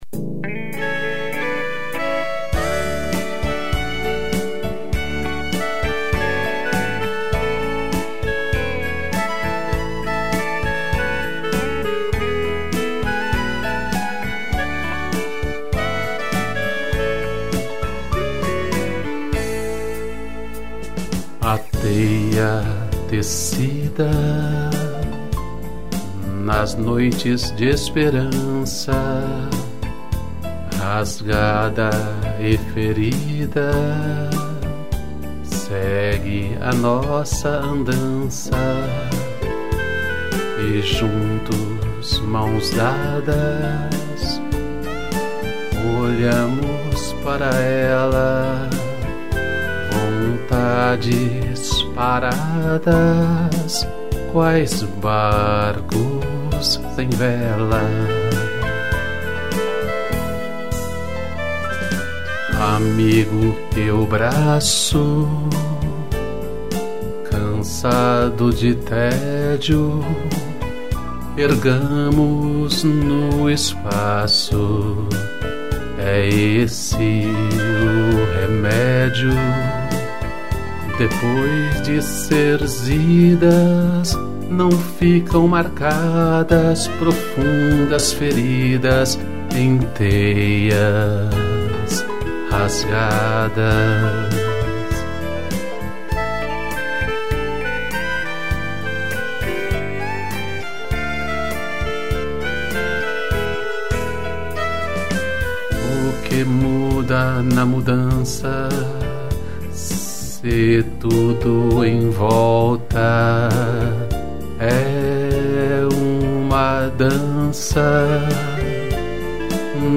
piano, violino e clarineta